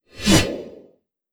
scythe.wav